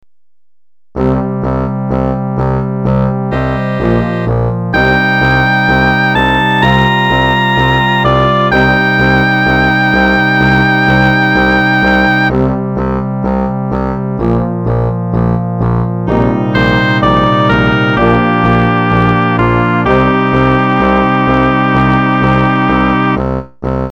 Mp3 Converted From Midi